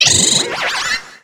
Catégorie:Cri de Hoopa Catégorie:Cri Pokémon (X et Y)